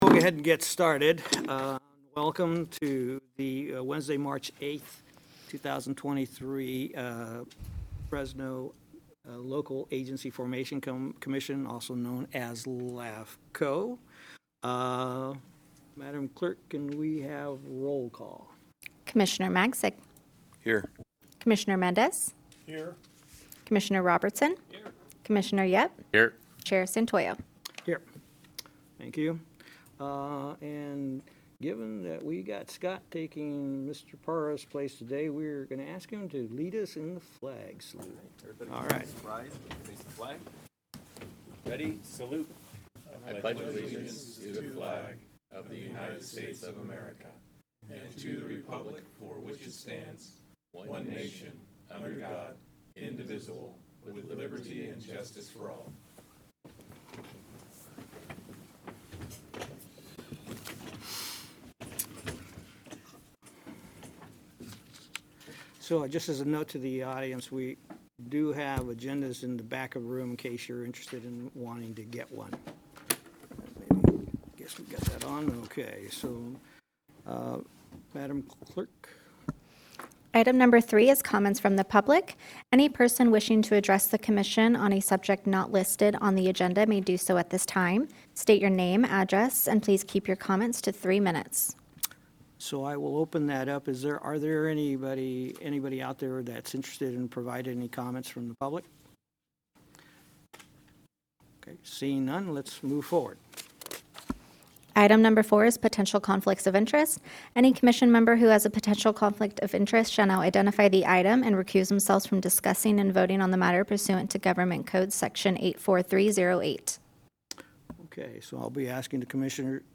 LAFCO Commission Hearing